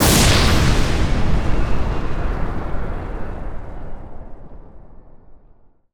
goodcircle/IdleRPG2024 - Assets/_8Sound/ZombieSkill_SFX/new/sfx_skill 04.wav at 1ca118a581ef2e7ece1ef1dd51b876c898126a91 - IdleRPG2024 - GoodCircle
sfx_skill 04.wav